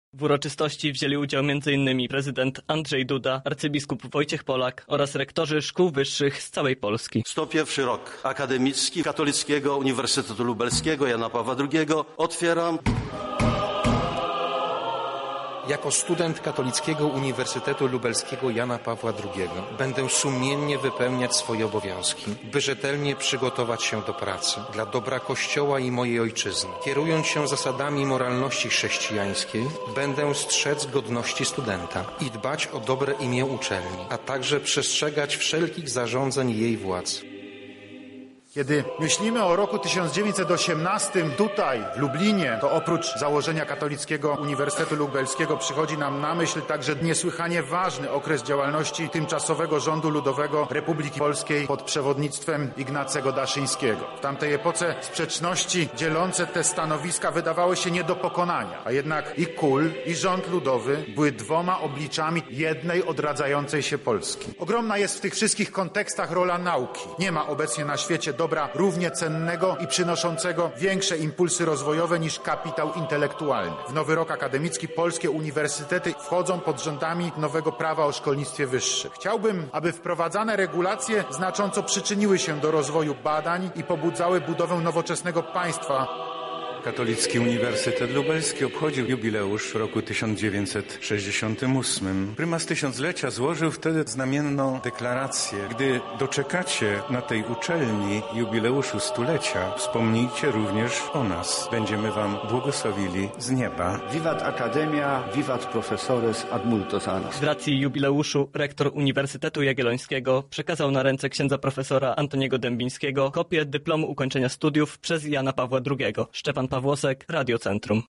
Była to także okazja do wręczenia odznaczeń dla wybitnych pracowników naukowych uczelni. Na miejscu był nasz reporter.